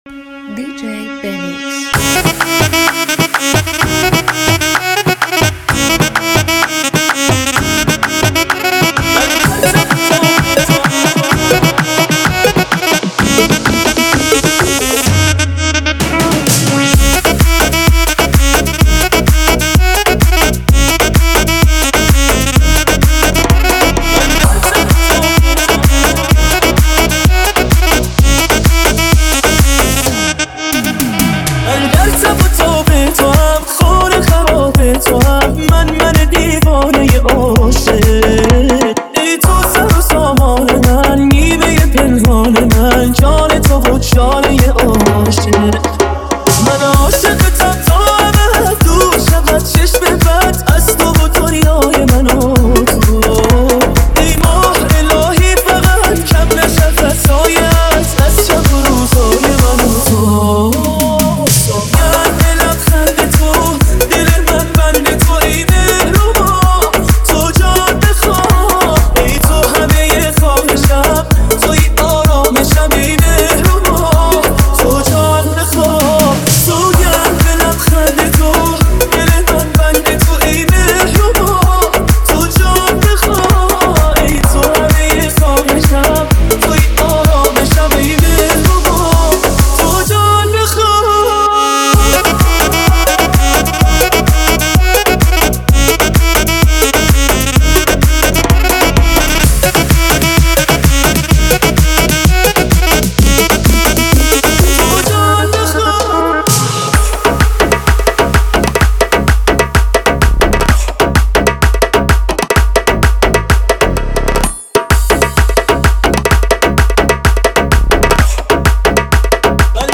ریمیکس شاد و پرانرژی آهنگ
موسیقی پاپ ایرانی